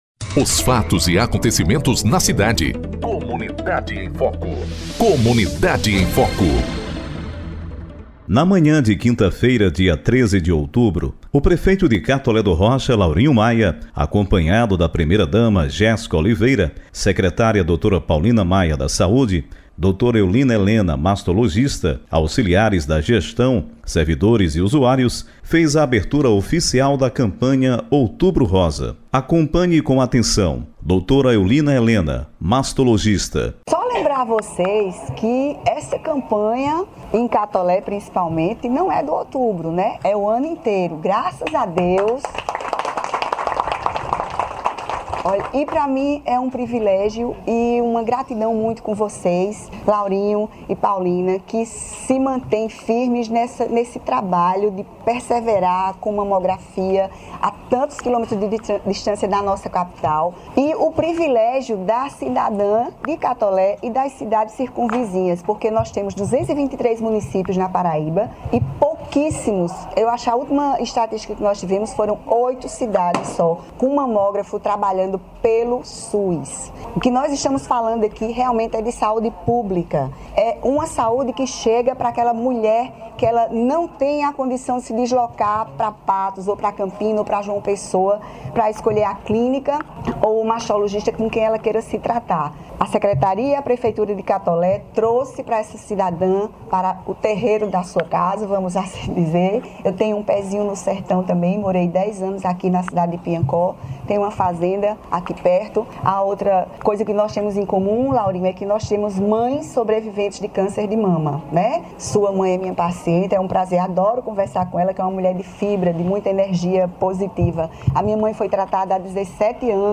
Prefeito Laurinho Maia fez a abertura oficial da campanha "Outubro Rosa".
Outubro-Rosa-prefeito-Laurinho-faz-abertura-da-campanha.mp3